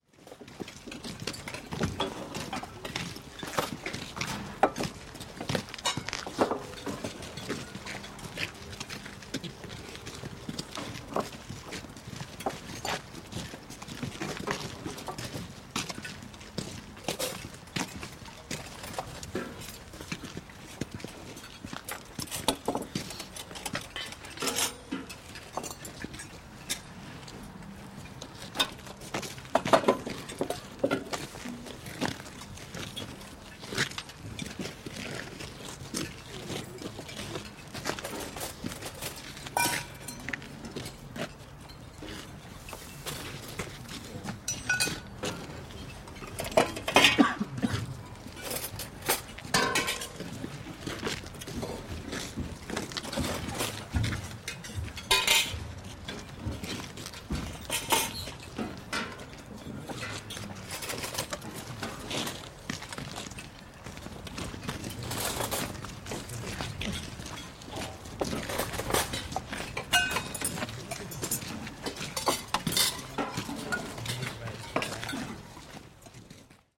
Шум солдатского лагеря в минуты отдыха